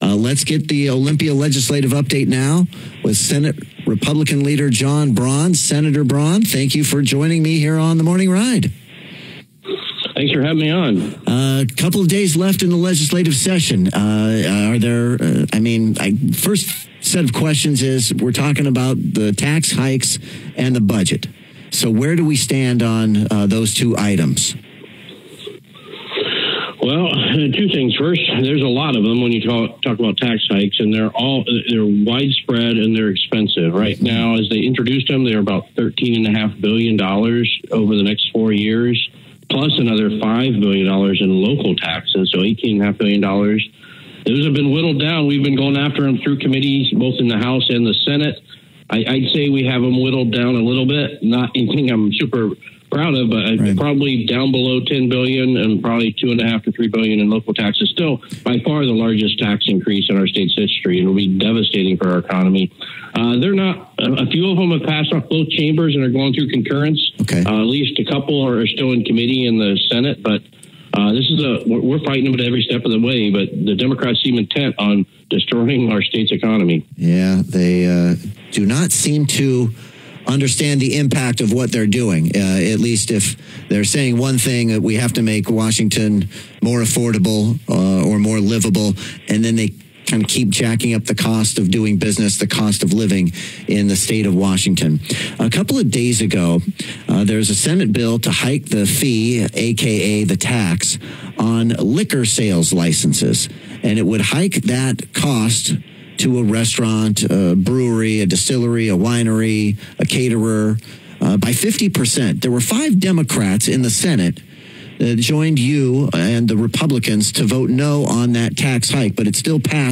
AUDIO: Senator John Braun Discusses Tax Hikes and Transparency on KVI Radio - Senate Republican Caucus